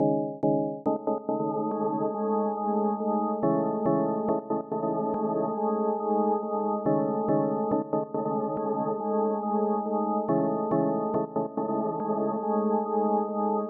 蒙大拿的300个钢琴循环曲
Tag: 140 bpm Rap Loops Piano Loops 1.15 MB wav Key : C